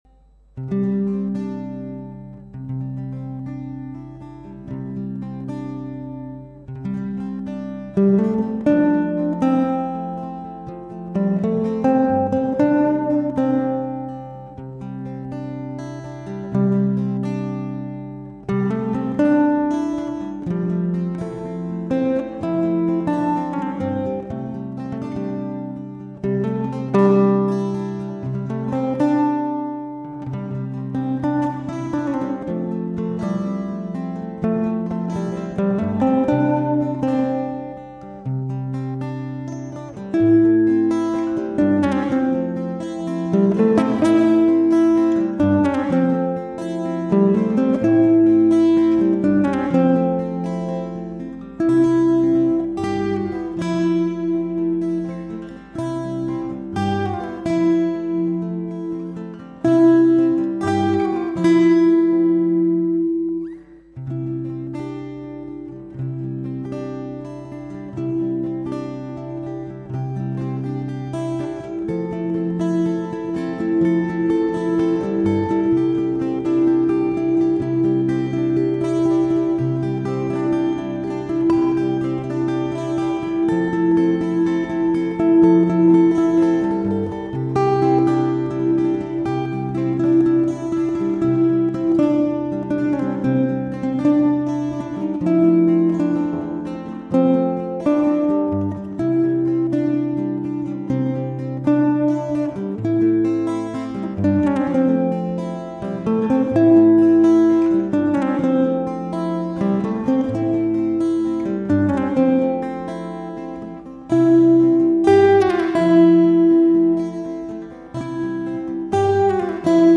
Tema/bakgrunds